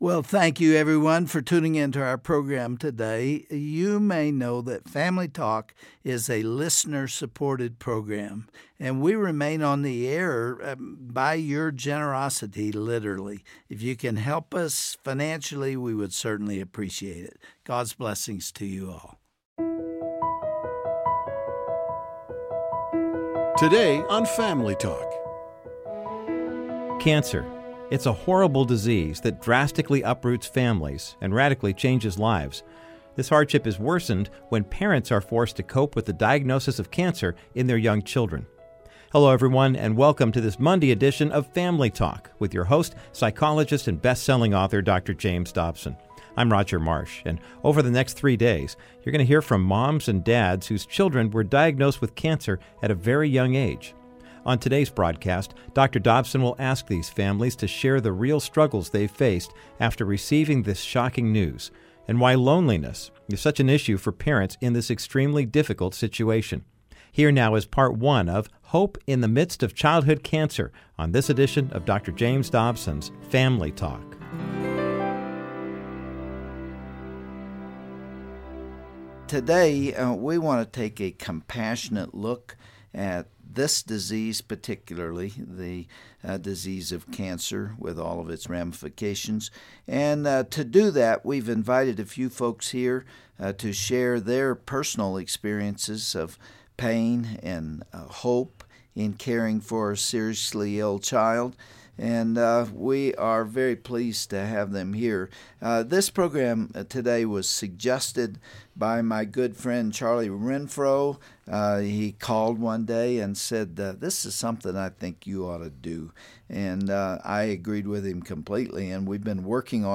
On this broadcast of Family Talk, Dr. Dobson will talk with multiple parents who have experienced childhood cancer, and, in some cases, lost their child. They will share their heartfelt stories and why they felt alone at times, on this edition of Dr. James Dobsons Family Talk.